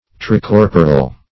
Search Result for " tricorporal" : The Collaborative International Dictionary of English v.0.48: Tricorporal \Tri*cor"po*ral\, Tricorporate \Tri*cor"po*rate\, a. [L. tricorpor; tri- (see Tri- ) + corpus, -oris, body.]